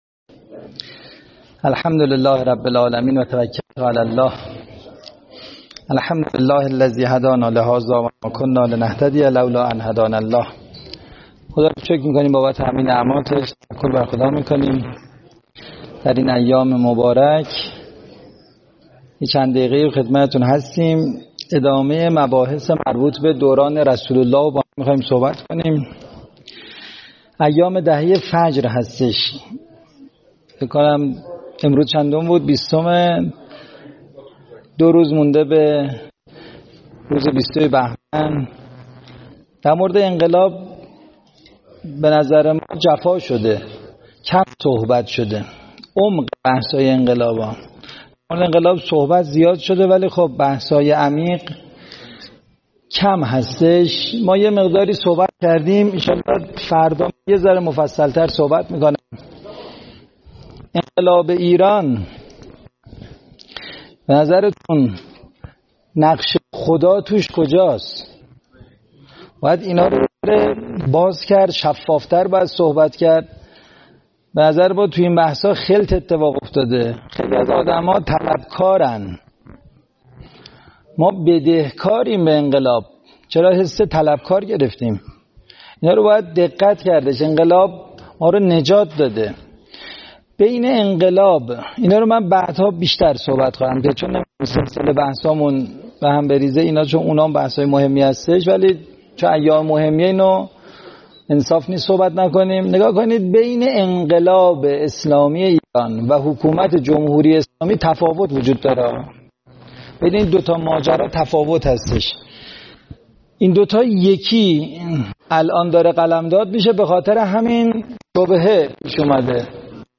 فایل صوتی سخنرانی